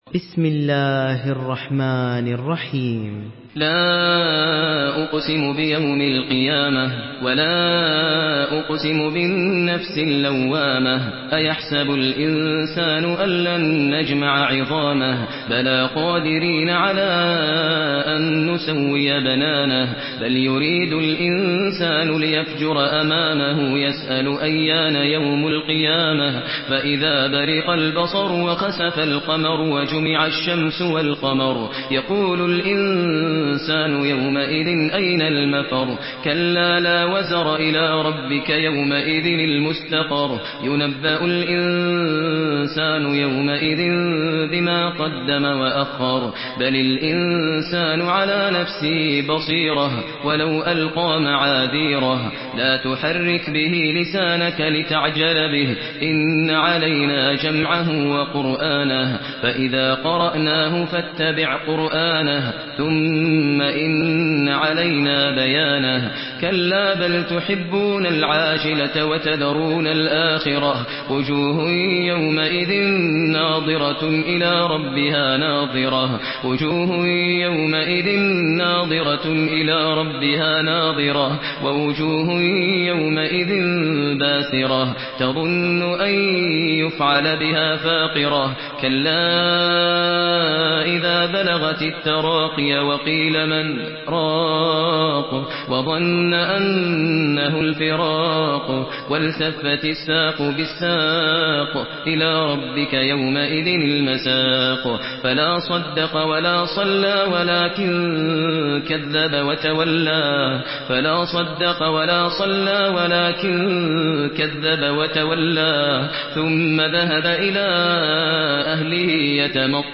Surah Al-Qiyamah MP3 in the Voice of Maher Al Muaiqly in Hafs Narration
Murattal